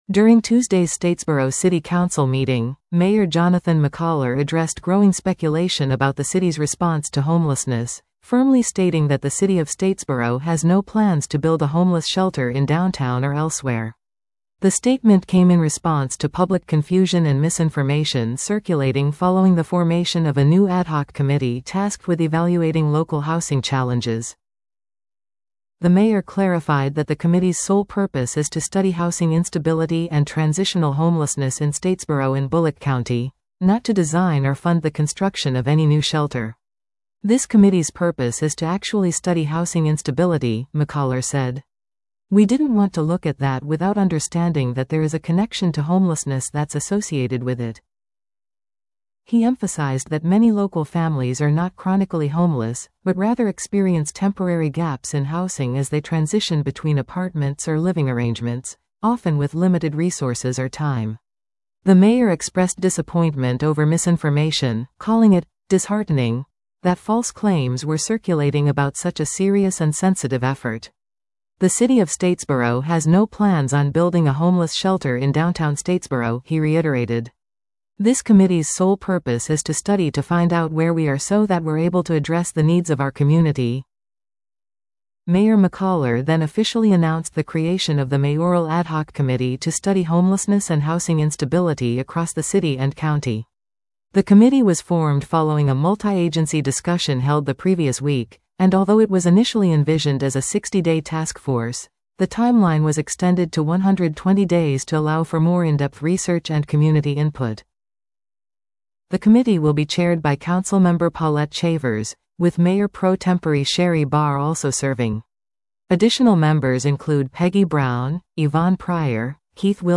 During Tuesday’s Statesboro City Council meeting, Mayor Jonathan McCollar addressed growing speculation about the city's response to homelessness, firmly stating that the City of Statesboro has no plans to build a homeless shelter in downtown or elsewhere.